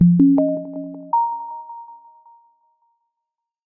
En Güzel 2026 Bildirim Sesleri İndir - Dijital Eşik